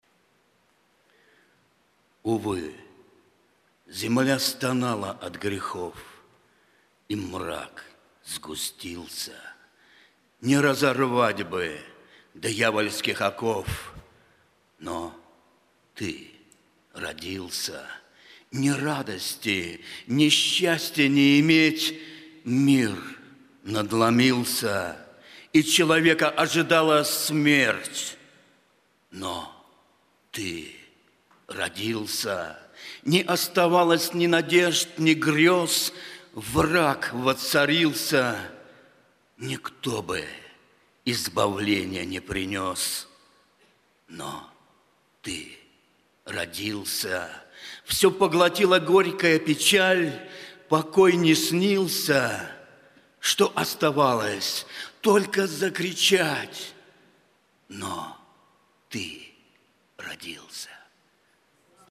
Богослужение 04.01.2023
Стихотворение